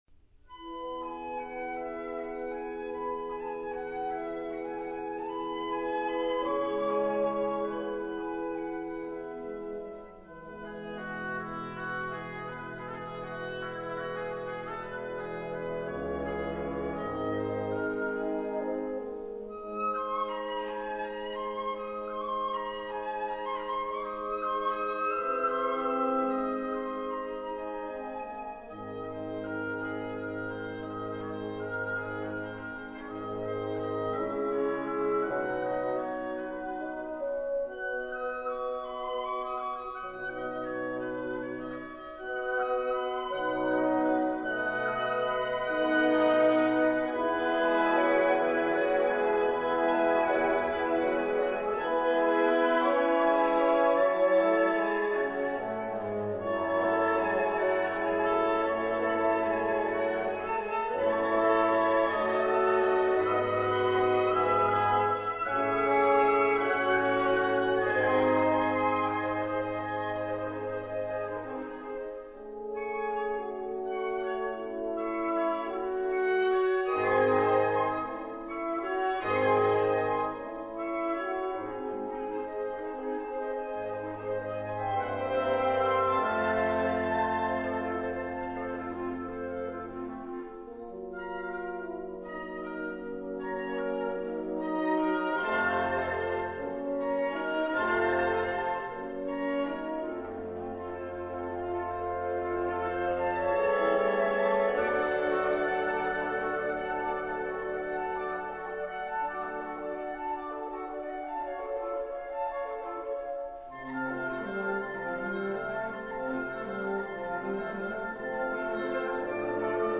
Per nove fiati
La celebre Suite nr. 1 op. 46 trascritta per 9 fiati.